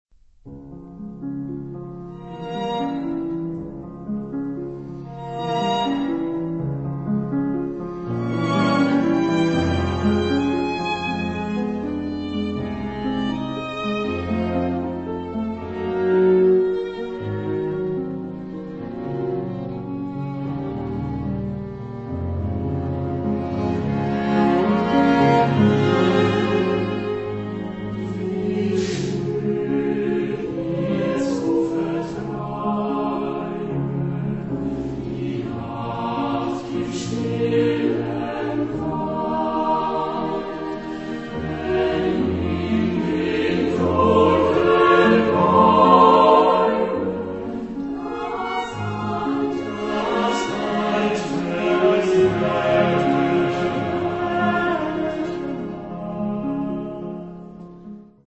Género/Estilo/Forma: Profano ; Romántico
Carácter de la pieza : andante
Tipo de formación coral: SATB  (4 voces Coro mixto )
Instrumentos: Violín (1) ; Viola (2) ; Violonchelo (1) ; Piano (1)
Tonalidad : mi menor ; re sostenido mayor